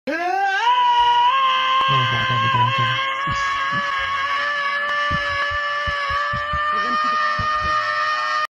Screaming Sound Effects MP3 Download Free - Quick Sounds